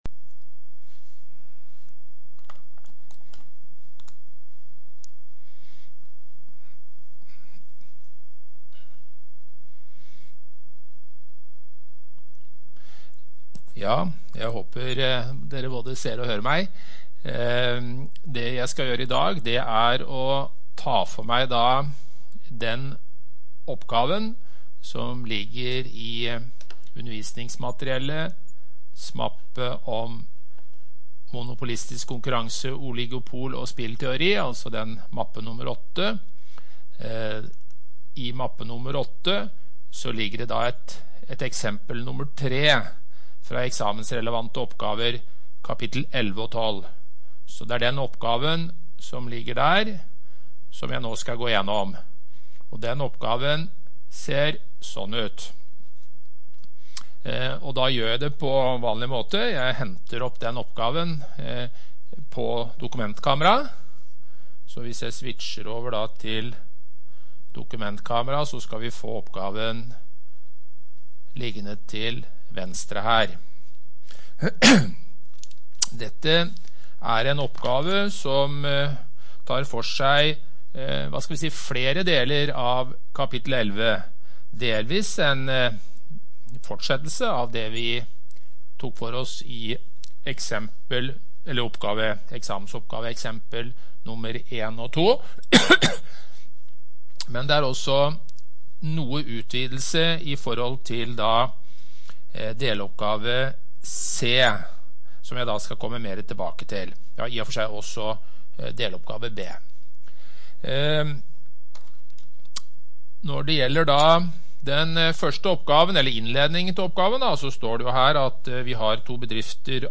Mikroøkonomi - NTNU Forelesninger på nett